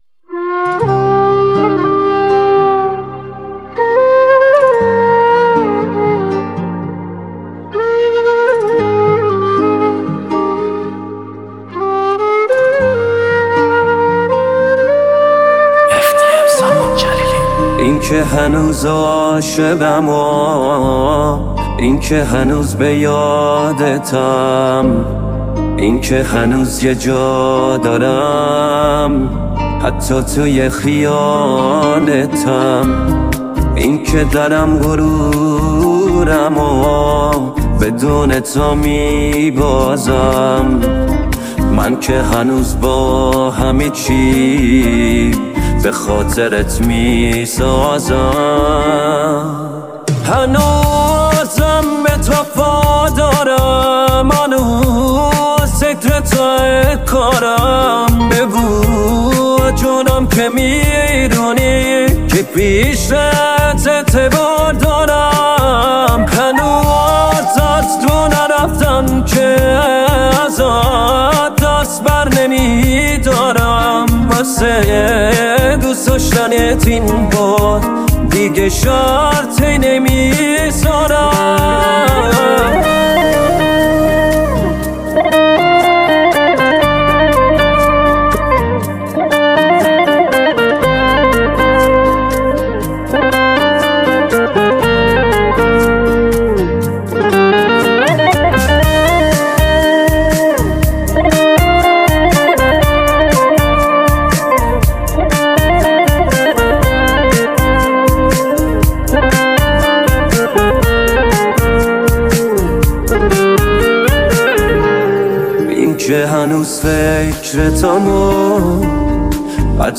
پاپ ایرانی